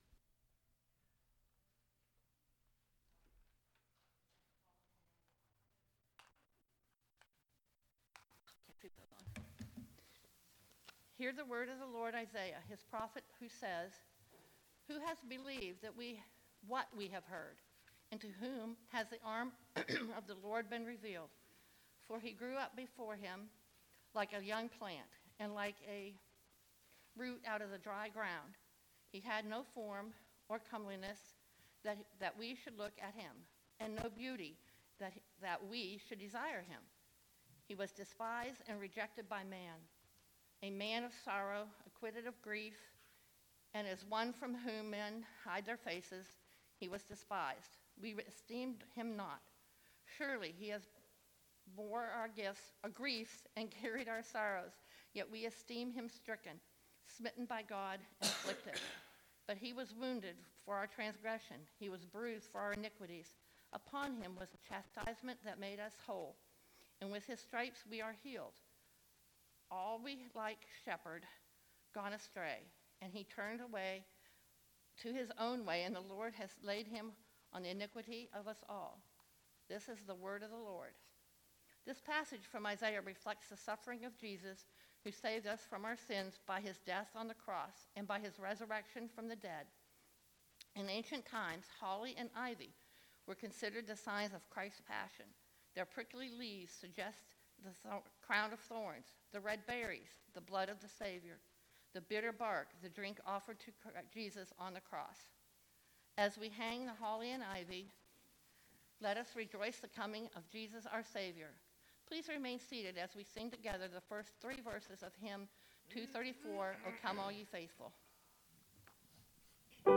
12-2-18 Service